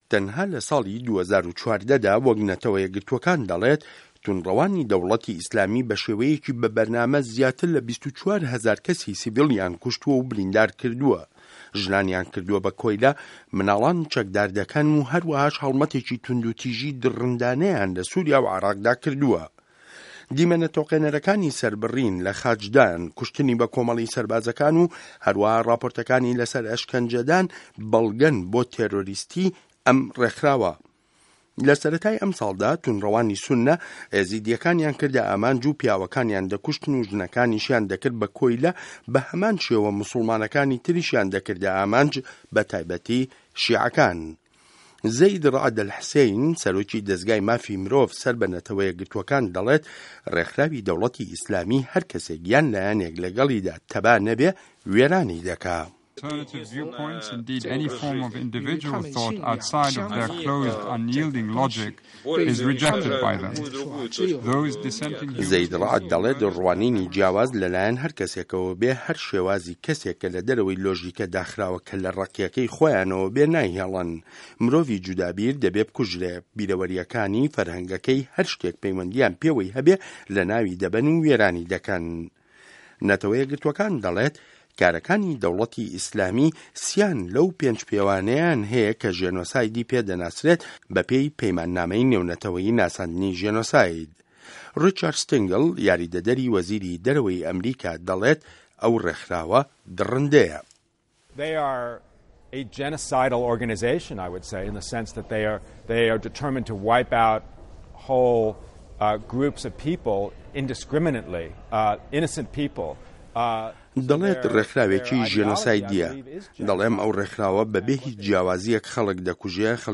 ڕاپـۆرتی داعش و کۆمه‌ڵ کوژی